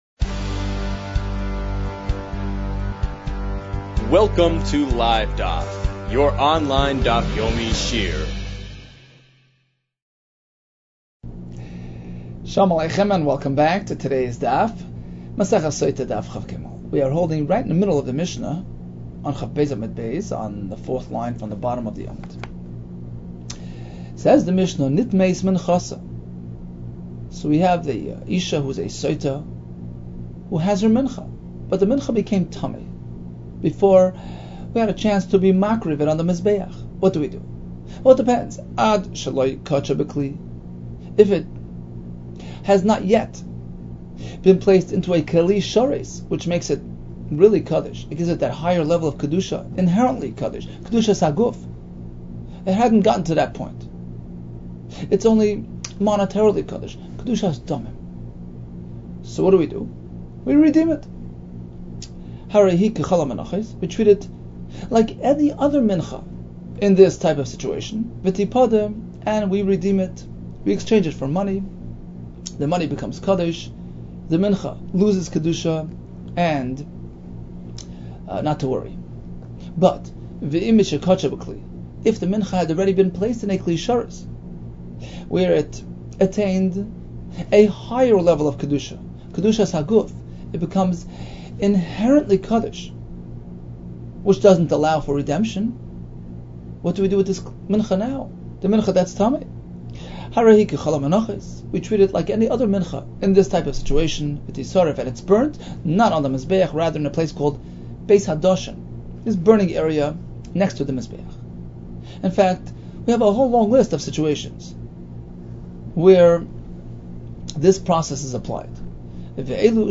Sotah 22 - סוטה כב | Daf Yomi Online Shiur | Livedaf